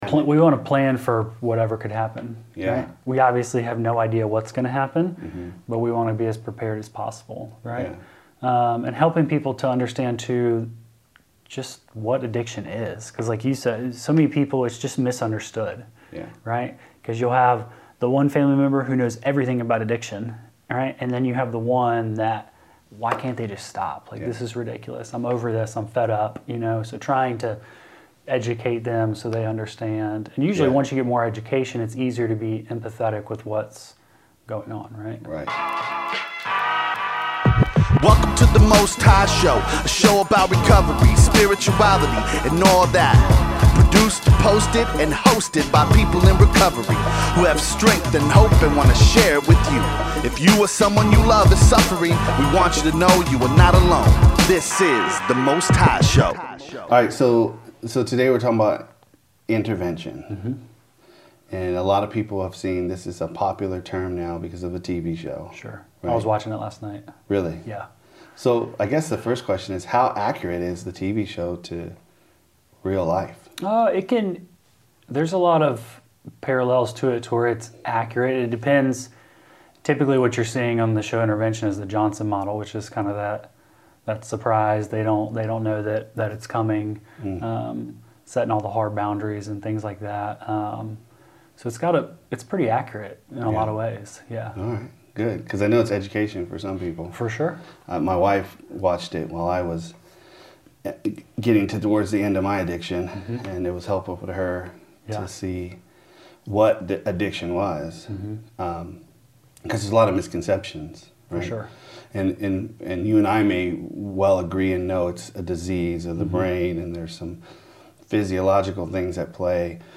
Interview with a Interventionist